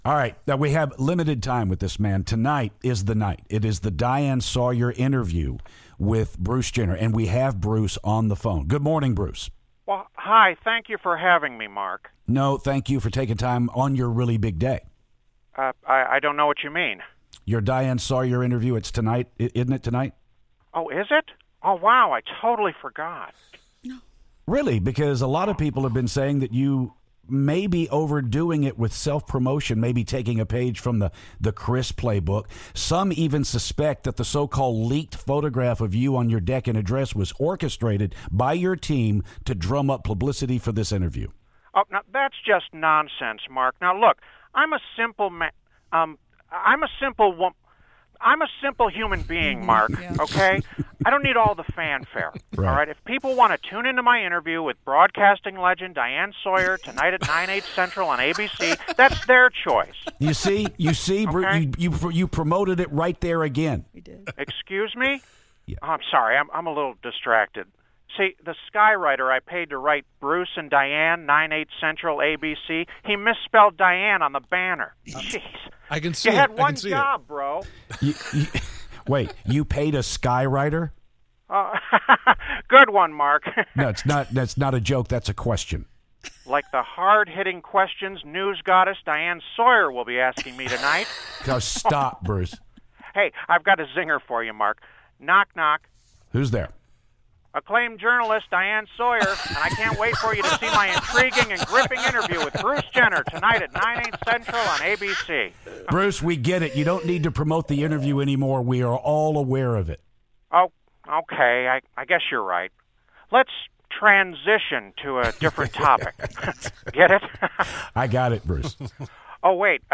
The guys get a very very very strange call from Bruce Jenner.